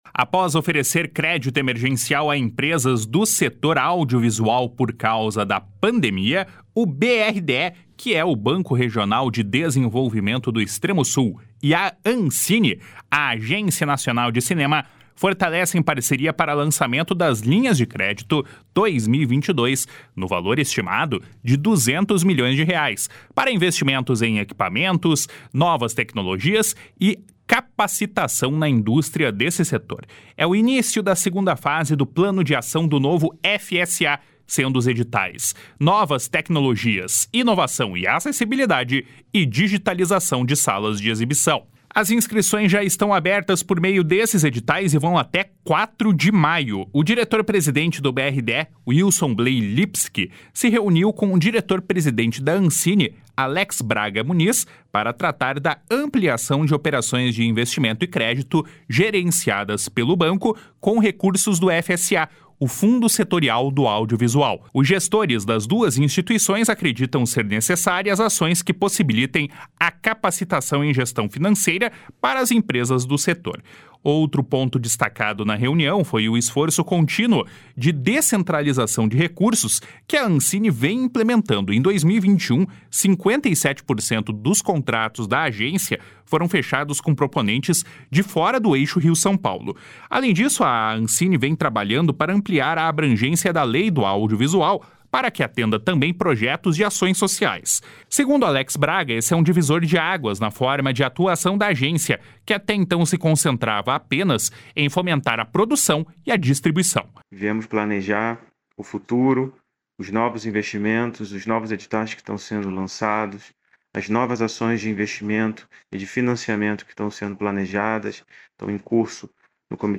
// SONORA ALEX BRAGA MUNIZ //